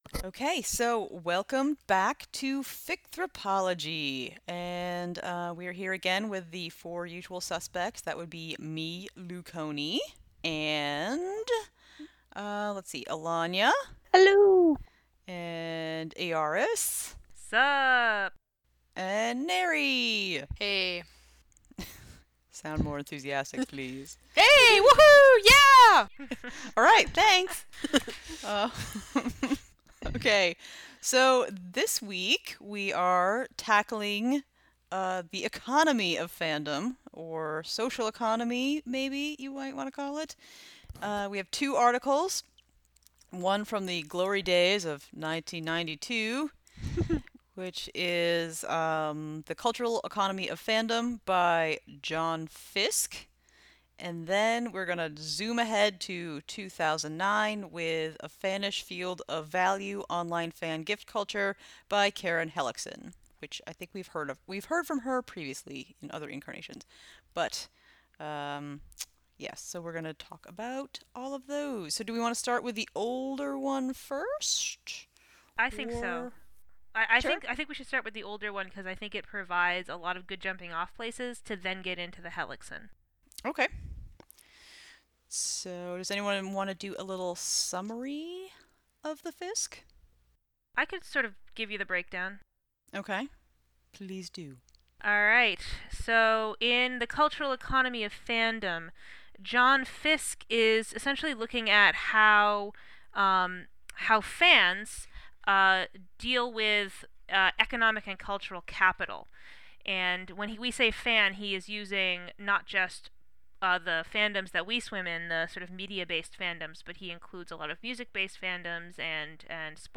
Notes: The podcast Ficthropology is designed as a discussion of the wonderful world of fan fiction from a critical standpoint. Modeled after an informal academic discussion group, we generally tackle one article, book, or theme per episode, from any field from anthropology, media studies, communications, women's studies - whoever has an interesting take of fanfic, its writers, readers, community, genres, themes, trends, or tropes.